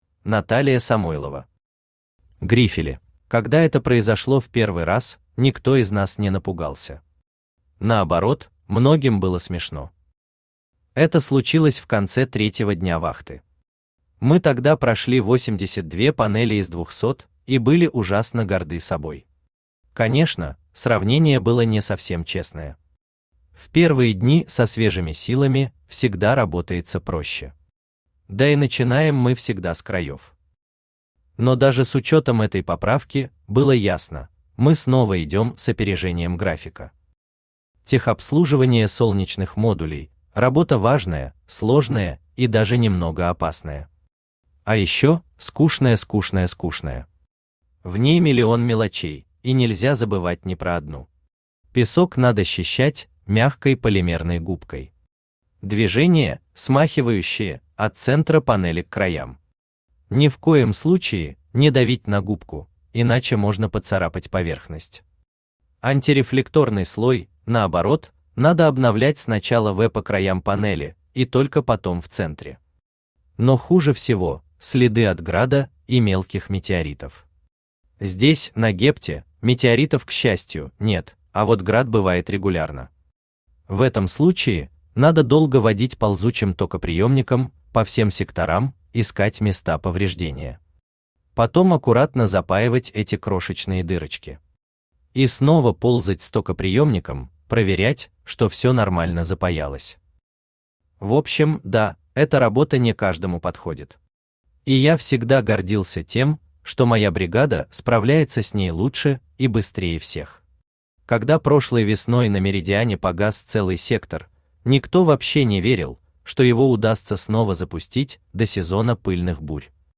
Aудиокнига Грифели Автор Наталия Самойлова Читает аудиокнигу Авточтец ЛитРес.